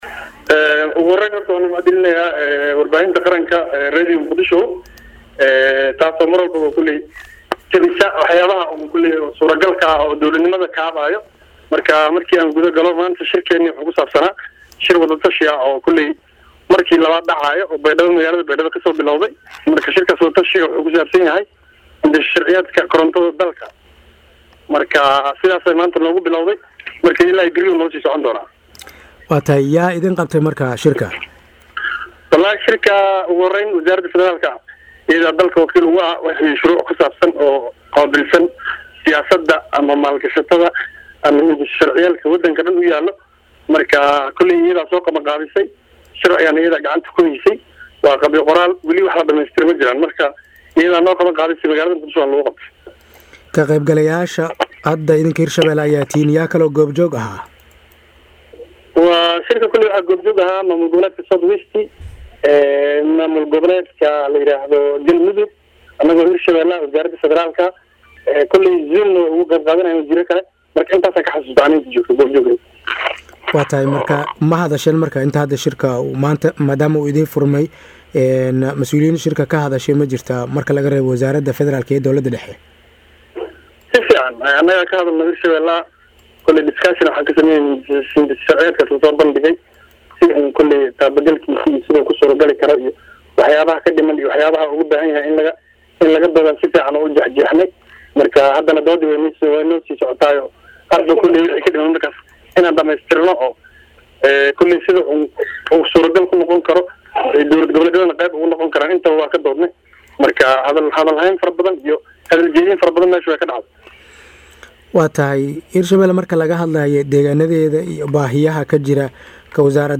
Wasiirka oo la hadlay Radio Muqdisho codka Jamhuuriyadda Soomaaliya ayaa rajo wanaagsan ka muujiyay waxyaabaha shirkaan kasoo bixi doona marka uu soo dhamaado.
Halkaan hoose ka dhageyso Wareysiga